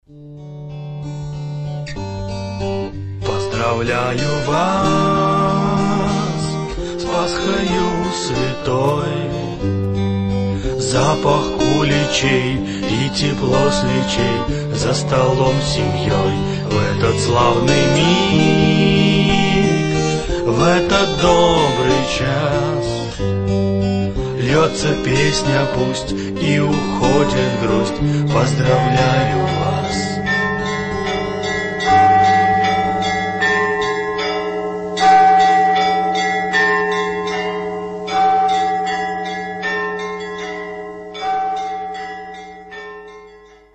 Теплые поздравления с Пасхой в песне